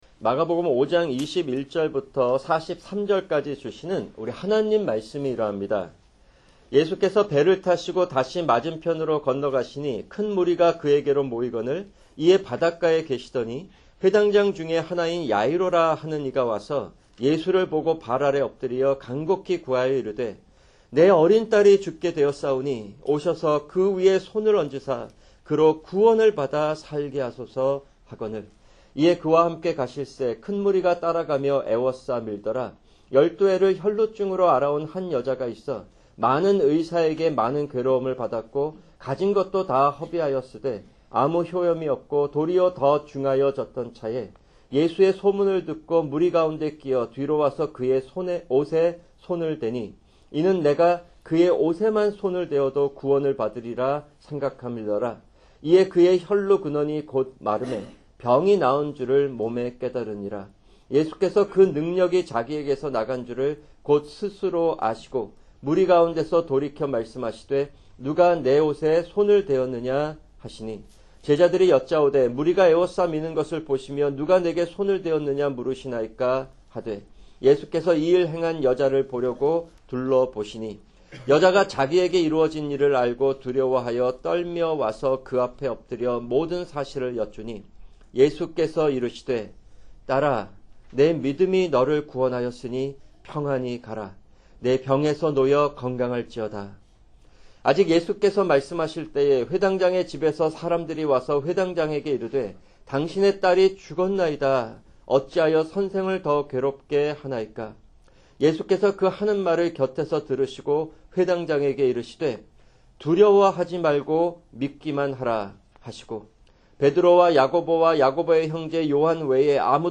[주일 설교] 마가복음(24) 5:21-43